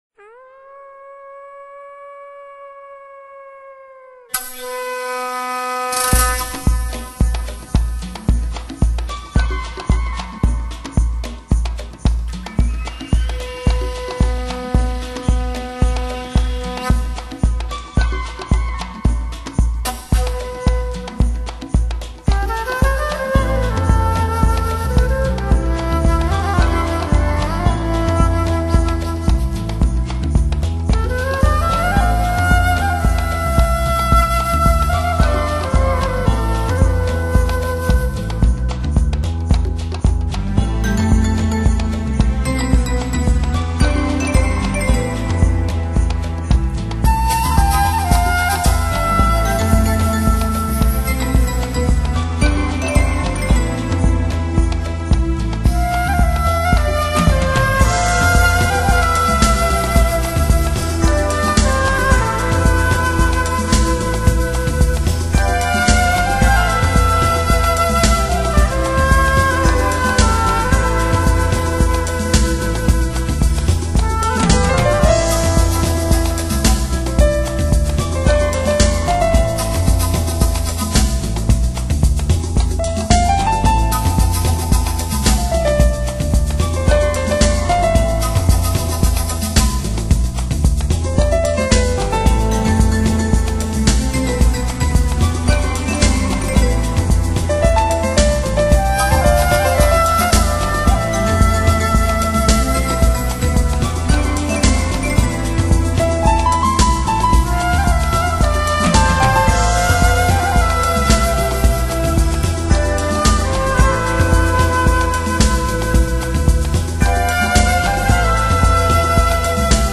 REAL MUSIC